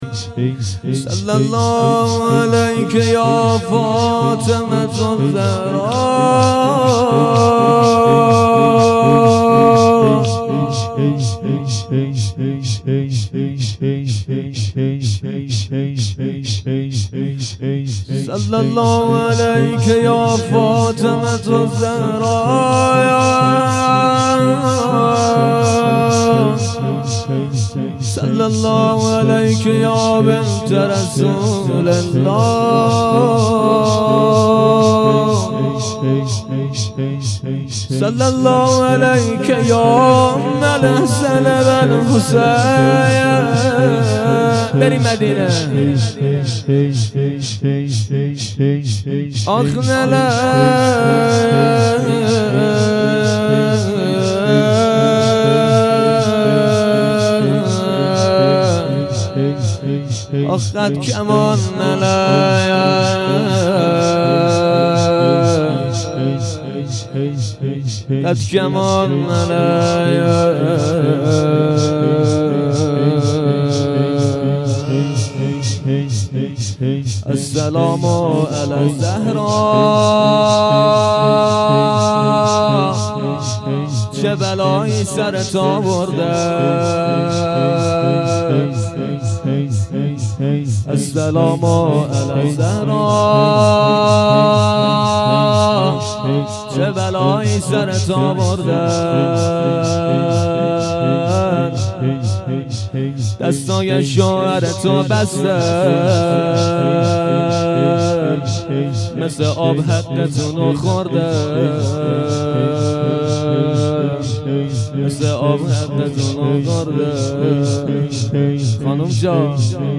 هیئت پیروان علمدار شهرری
شب اول فاطمیه۹۶/۱۱/۱۰